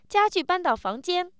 happy